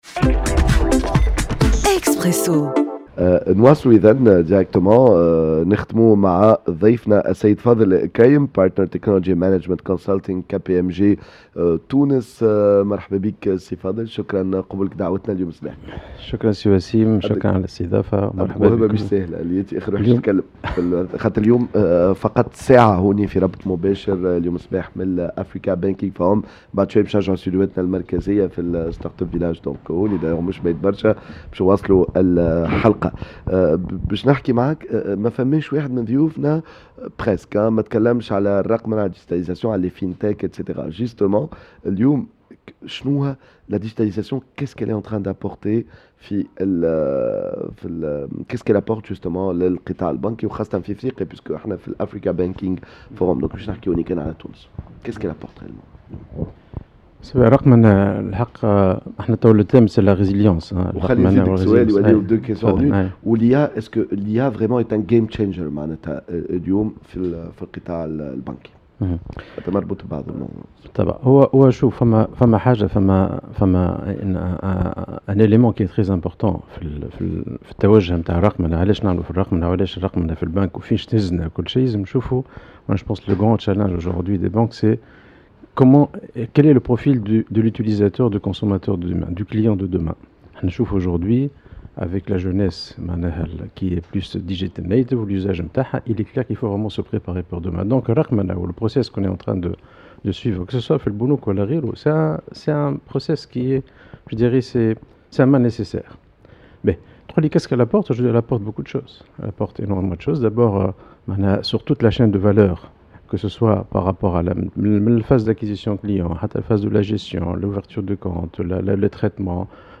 dans un plateau spécial